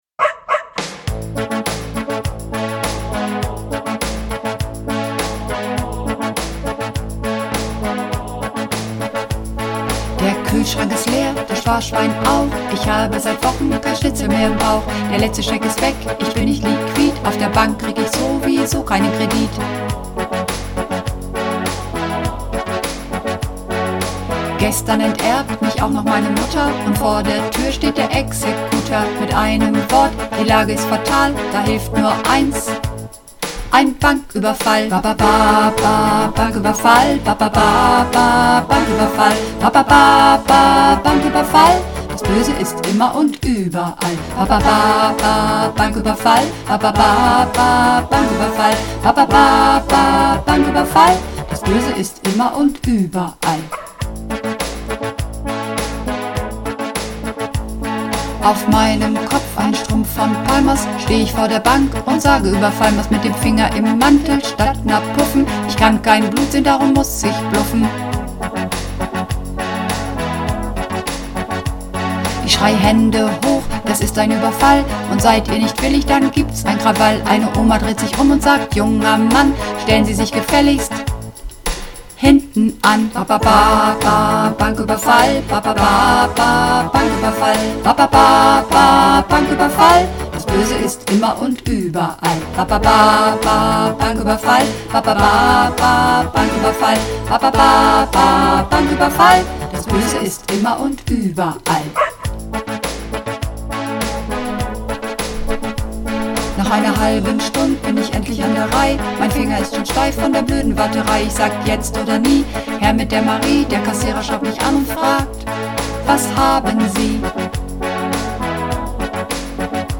Übungsaufnahmen - Ba-Ba-Banküberfall
Runterladen (Mit rechter Maustaste anklicken, Menübefehl auswählen)   Ba-Ba-Banküberfall (Mehrstimmig)